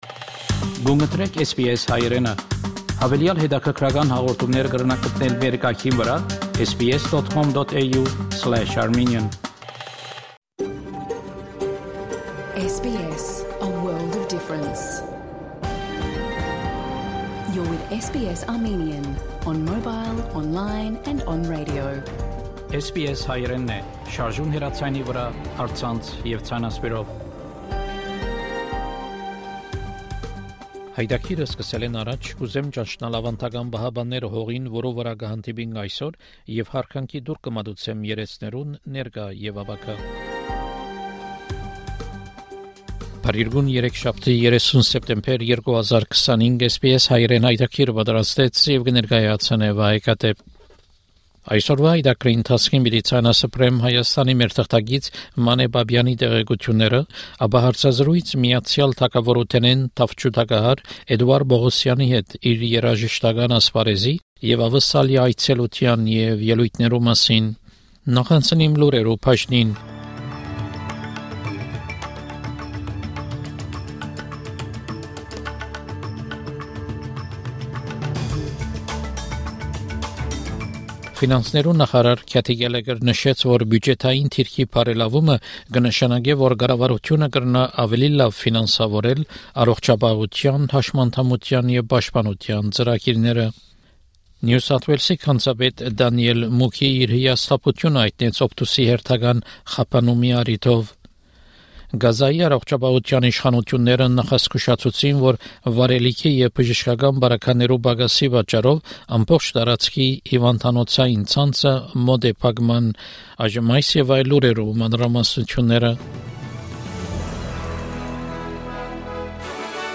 SBS Armenian news bulletin from 30 September 2025 program.